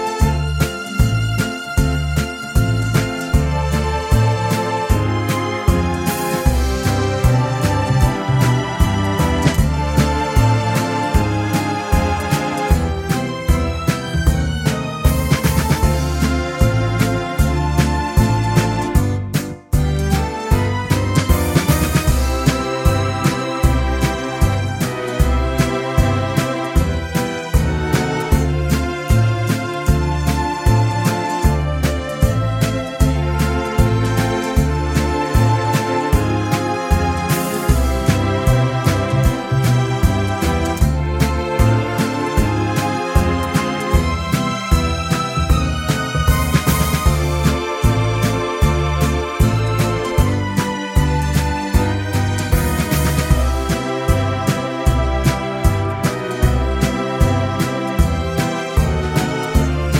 no sax Pop (1970s) 3:25 Buy £1.50